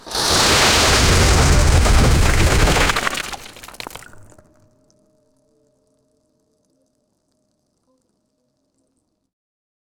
Whispers rising into a loud, chaotic chorus
whispers-rising-into-a-lo-esks62cw.wav